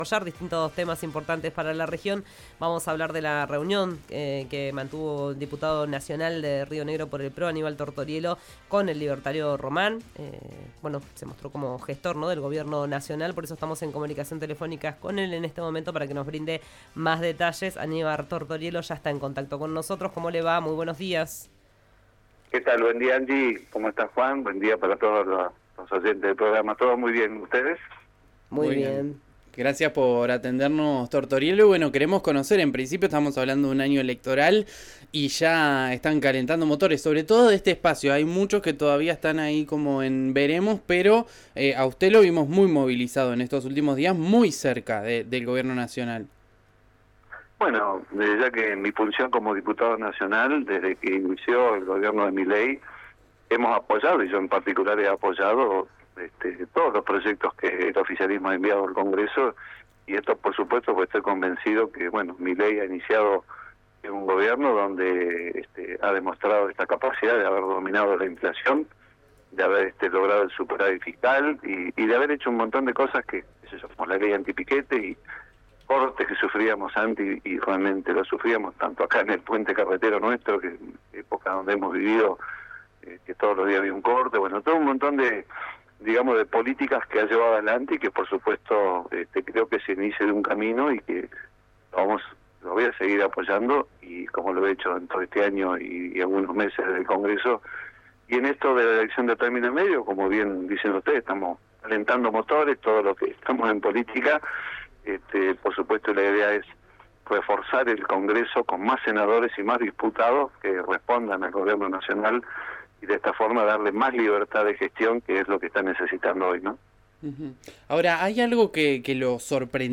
Escuchá a Aníbal Tortoriello, diputado nacional de Río Negro por el PRO, en RÍO NEGRO RADIO: